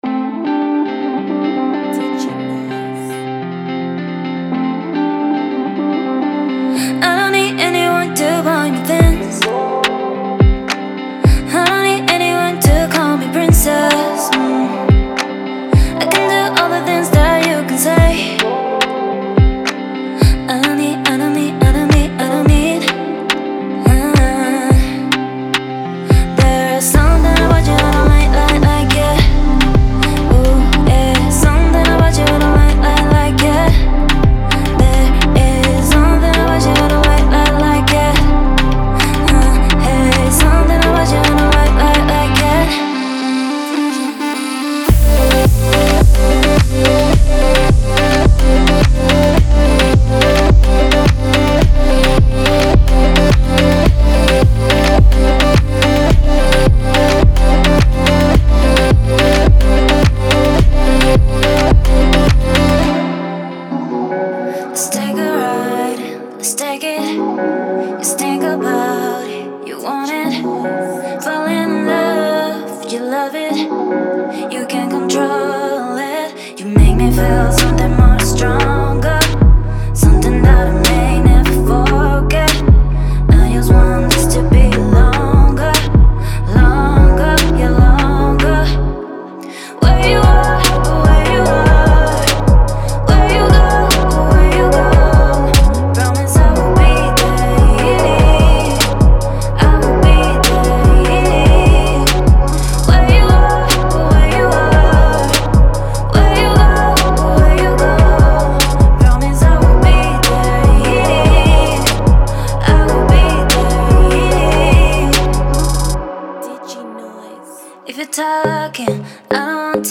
Demo song
• 10 Vocal parts (5 dry + 5 wet)